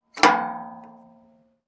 Metal_79.wav